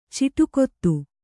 ♪ ciṭukottu